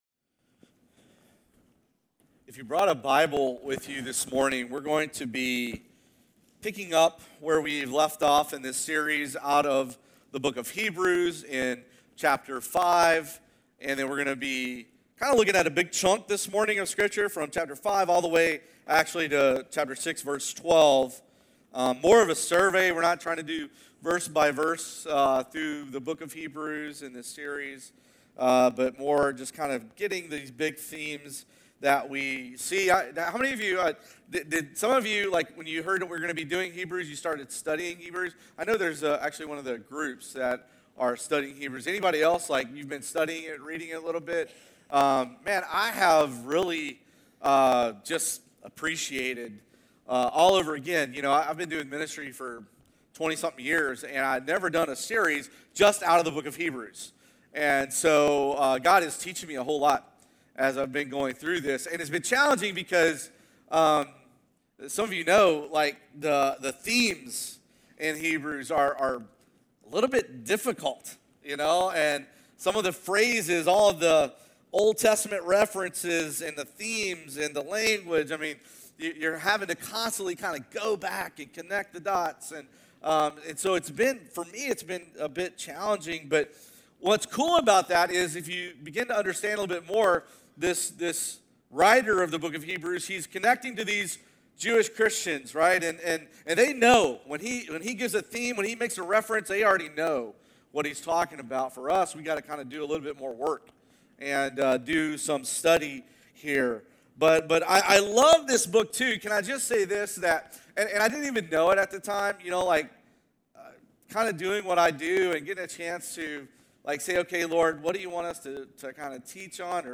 Sermons | Campbellsville Christian Church